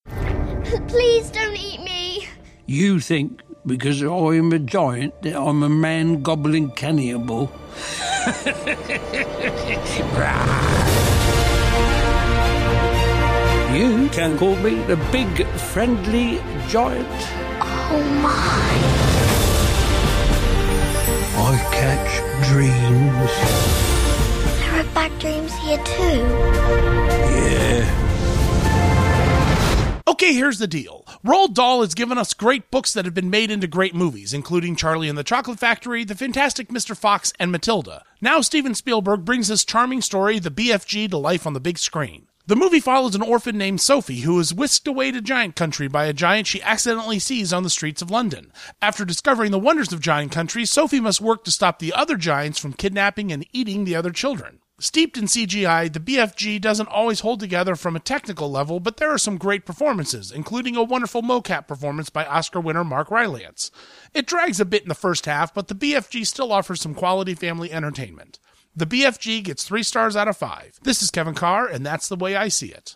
‘The BFG’ Radio Review